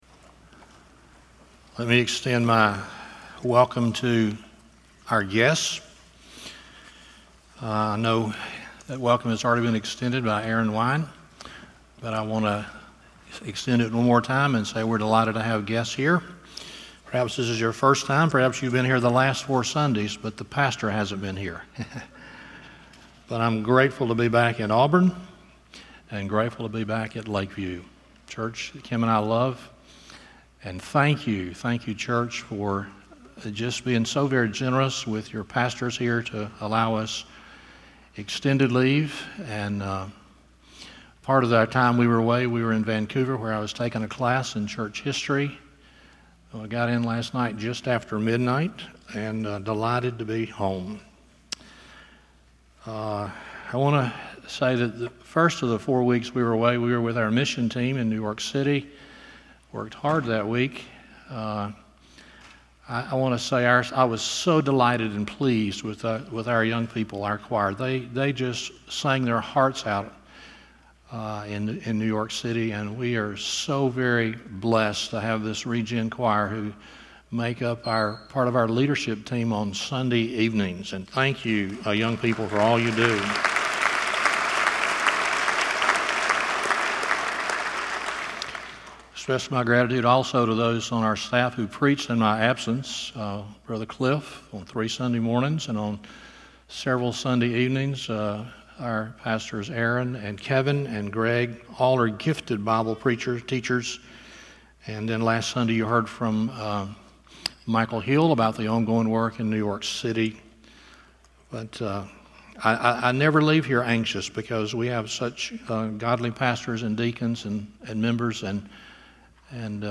Stand Alone Sermons Passage: Matthew 19:13-15 Service Type: Sunday Morning (CHILDREN'S DAY) 1.